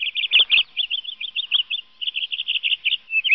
Add canary sounds
sounds_canary_03.ogg